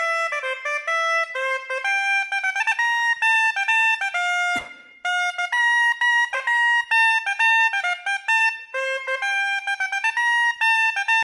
DIY Learn a Language - Ukrainian Musical Instruments
Surma
Surma.mp3